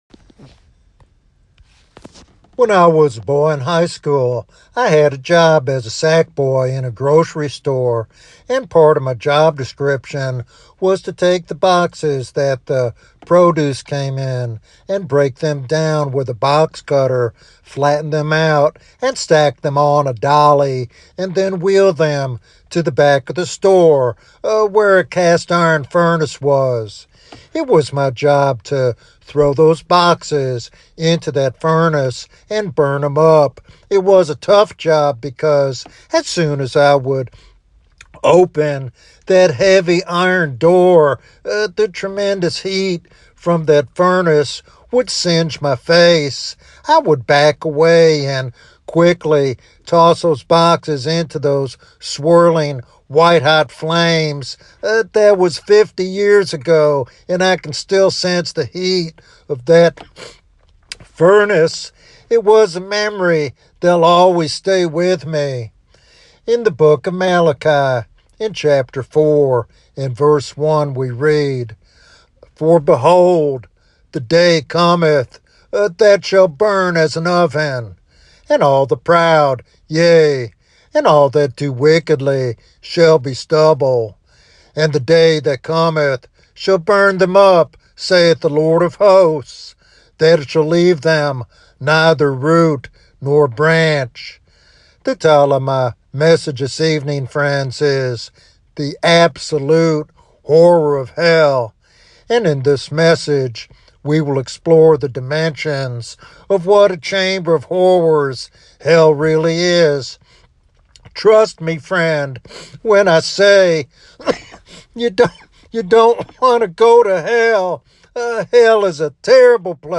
This sermon serves as a solemn warning and a call to repentance, urging listeners to turn to Christ before it is too late.